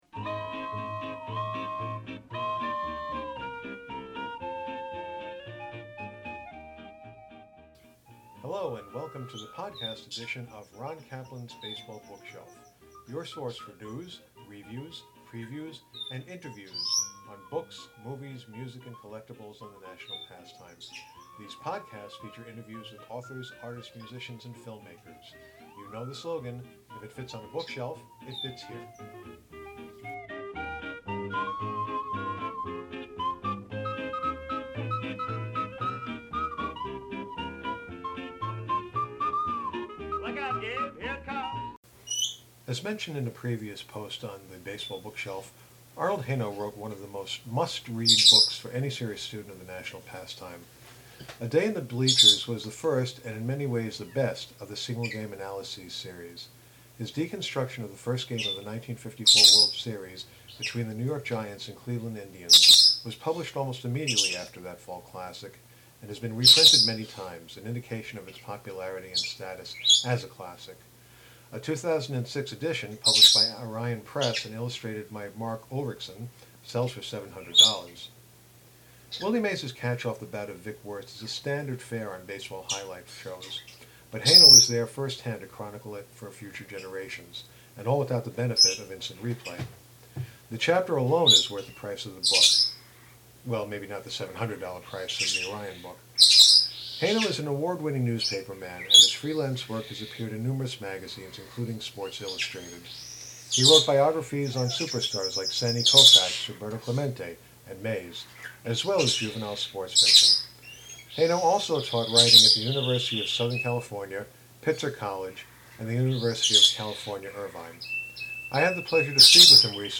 I had the pleasure to speak with him recently about the decision to write A Day in the Bleachers , and whether such a book could be done in the amusement facilities that serve as modern day ballparks. (My apologies for the bird sounds and other blips and bleeps.)